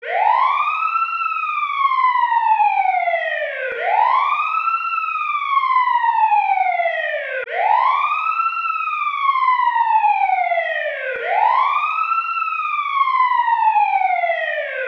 siren2.wav